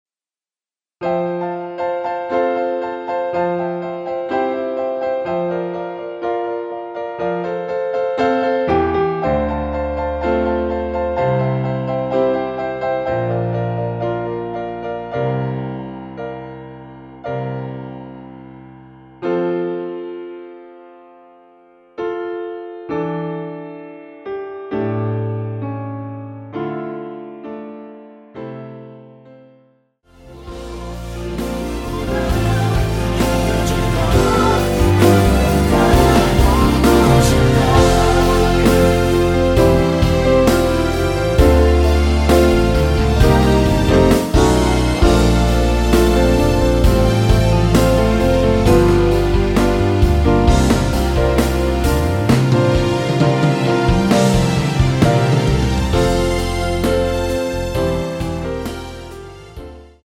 (-1)내린 코러스 포함된 MR 입니다.(미리듣기 참조)
◈ 곡명 옆 (-1)은 반음 내림, (+1)은 반음 올림 입니다.
앞부분30초, 뒷부분30초씩 편집해서 올려 드리고 있습니다.
중간에 음이 끈어지고 다시 나오는 이유는